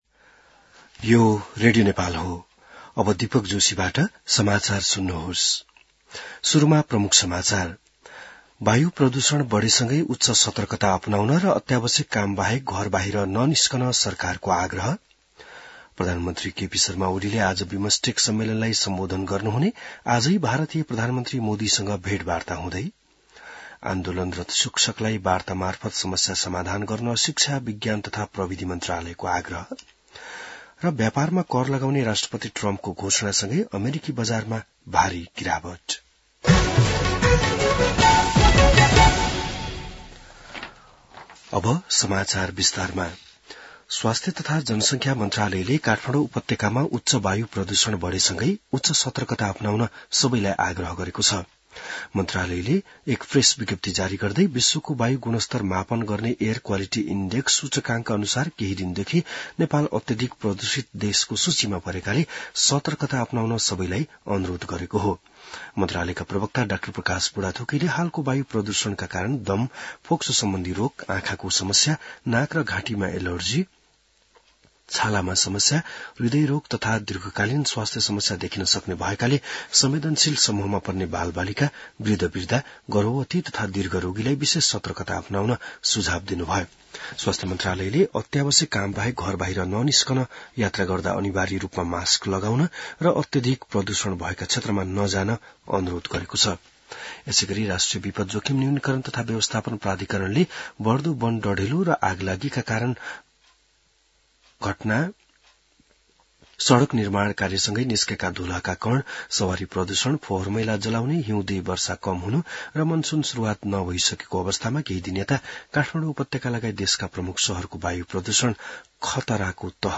बिहान ९ बजेको नेपाली समाचार : २२ चैत , २०८१